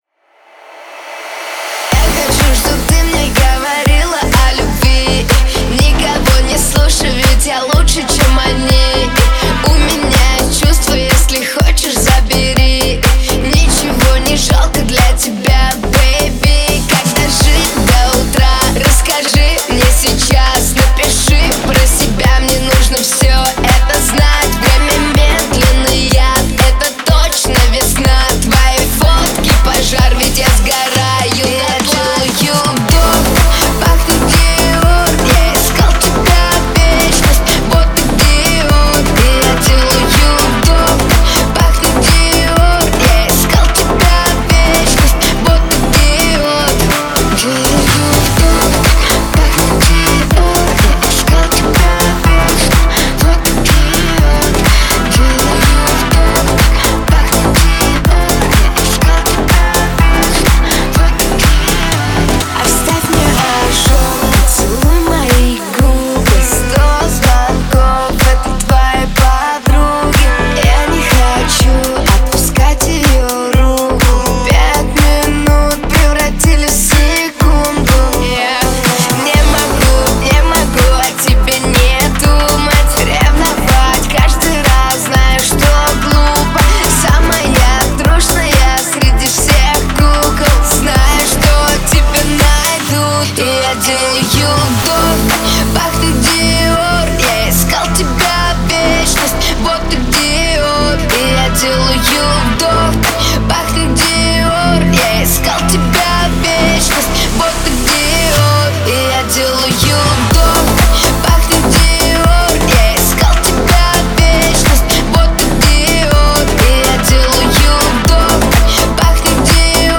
динамичная и энергичная песня